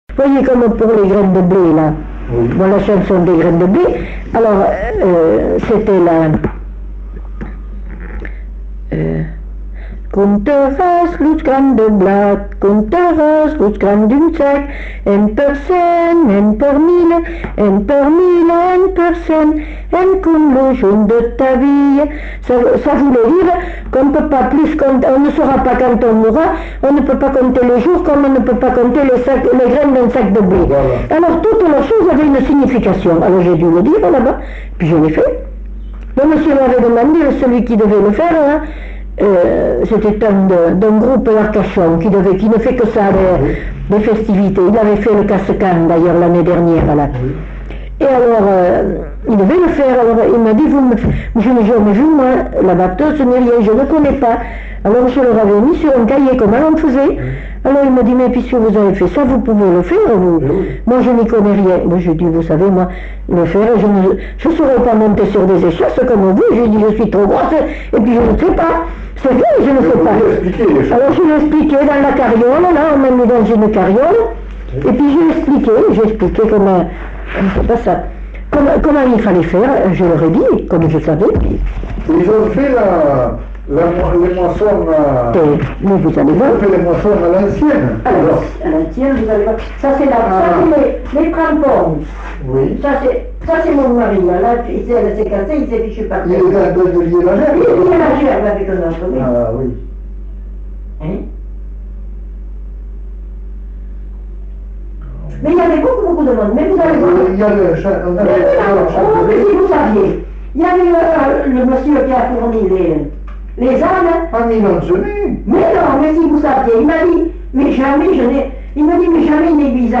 Lieu : Belin-Beliet
Genre : parole
Ecouter-voir : archives sonores en ligne